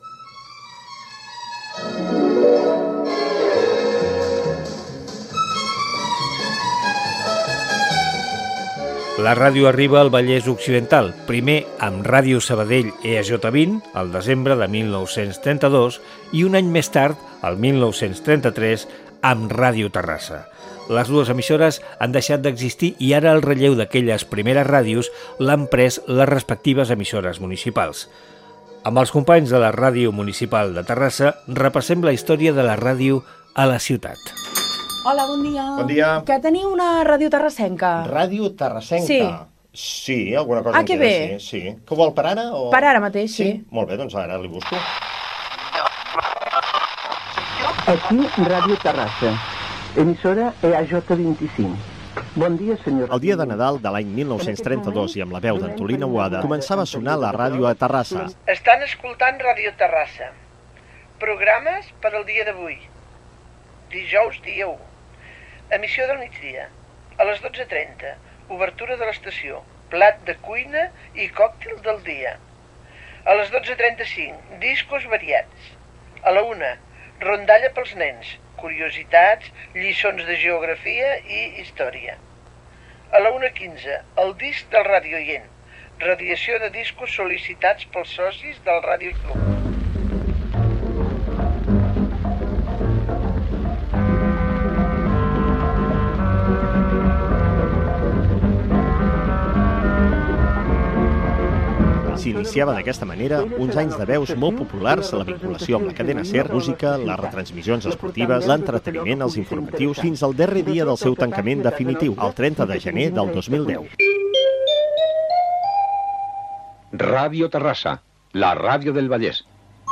ece8d5ea6670ca6f9f87aae0af0aca99c059e156.mp3 Títol La Xarxa Emissora Noucinc.2 Ràdio Cadena La Xarxa Titularitat Pública nacional Nom programa La ràdio, històries de 100 anys Descripció Emès amb motiu del Dia Mundial de la Radio 2024.
Gènere radiofònic Divulgació